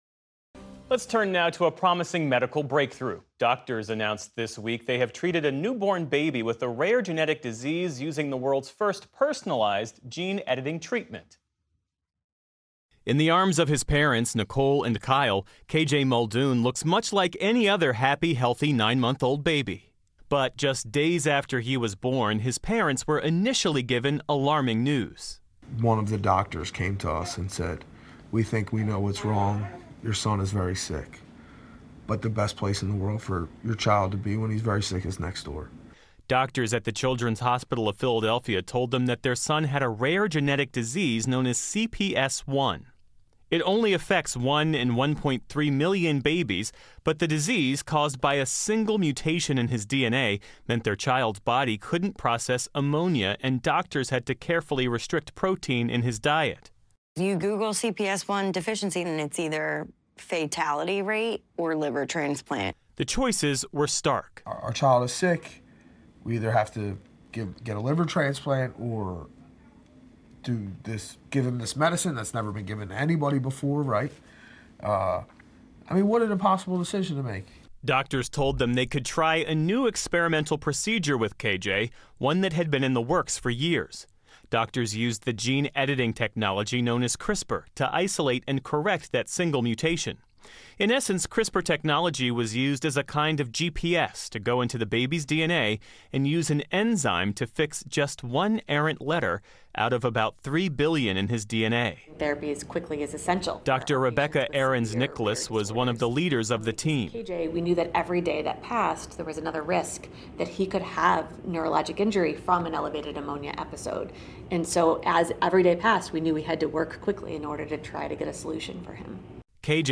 Doctors announced this week that they have treated a newborn baby with a rare genetic disease using the world’s first personalized gene editing therapy. Geoff Bennett discussed the treatment and its potential with Dr. Peter Marks.